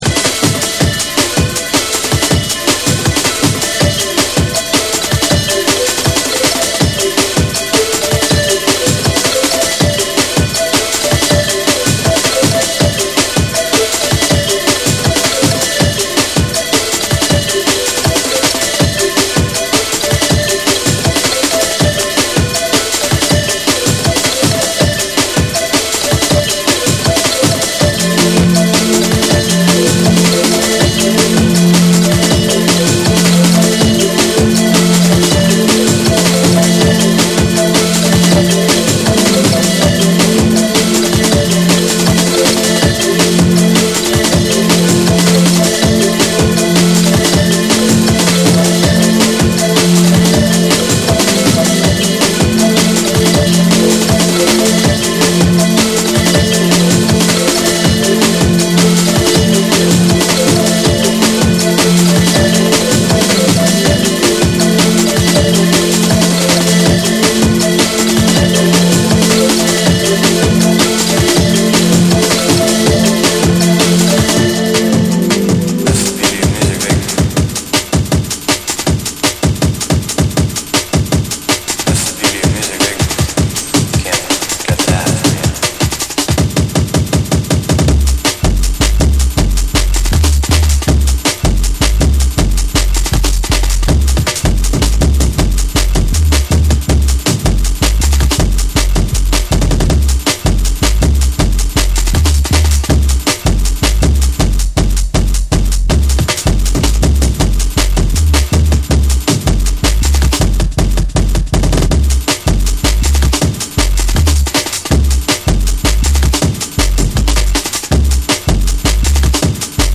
ギャラクティックなシンセが印象的なドラムンベースを披露する
BREAKBEATS